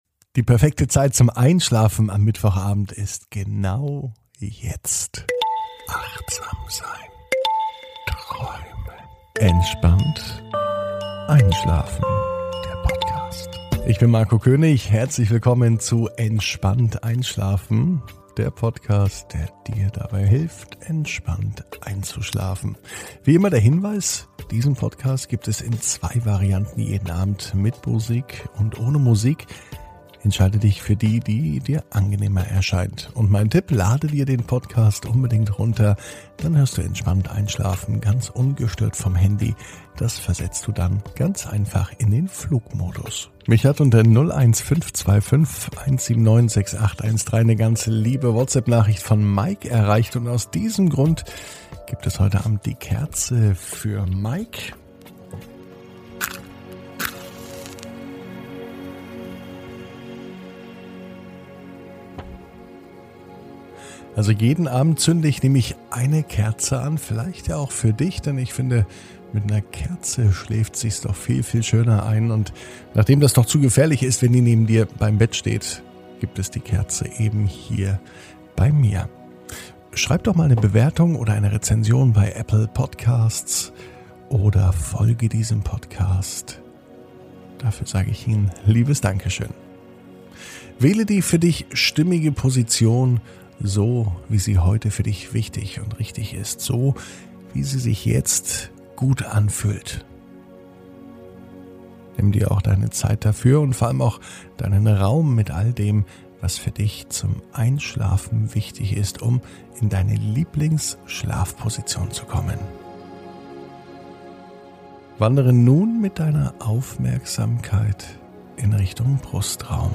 Heute startet der neue Einschlafpodcast Entspannt einschlafen.